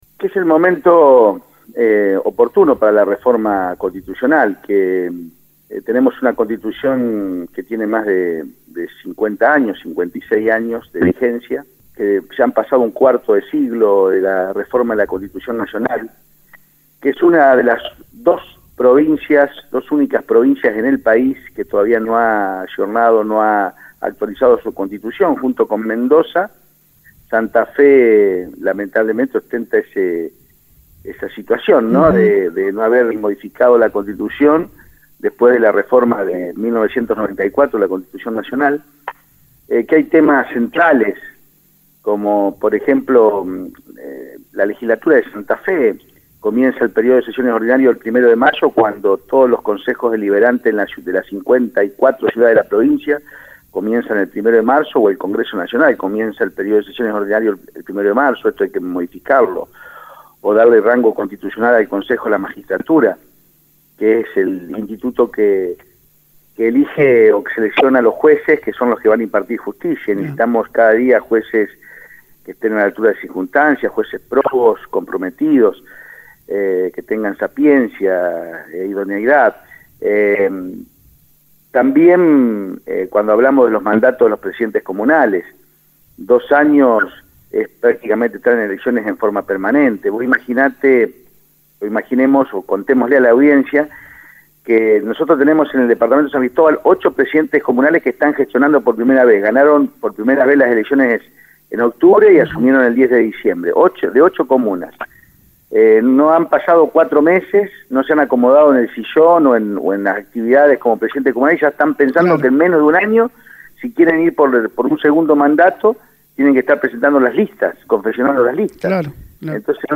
El Senador Felipe Michlig mantuvo un contacto con la emisora ceresina donde habló de diferentes temas. Reforma constitucional, obras para escuelas de Ceres, Fiesta de la Confraternidad y tarifas de la Luz.